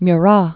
(my-rä, mü-), Joachim 1767-1815.